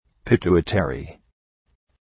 Προφορά
{pı’tu:ı,terı}